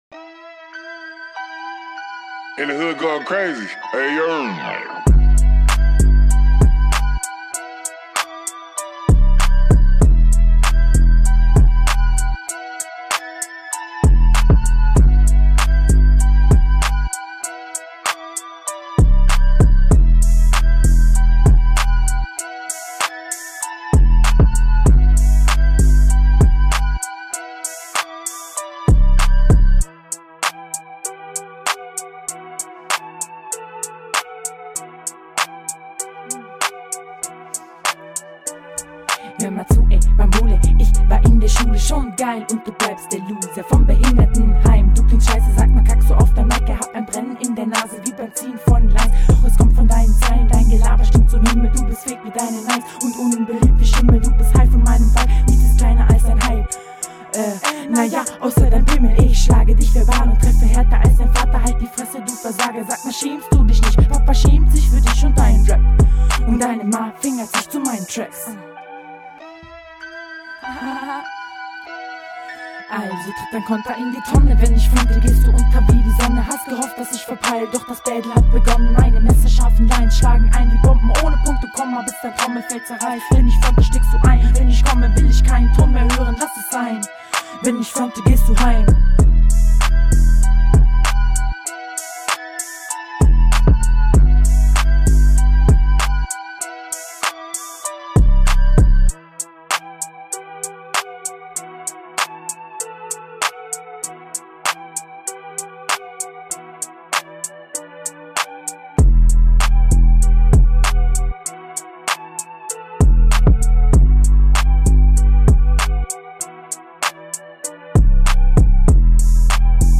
Diese Runde klingt nach Folter. Teilweiße unverständlich, monotoner Stimmeneinsatz, …
Geiler Beat, aber intro ist viel zu lang.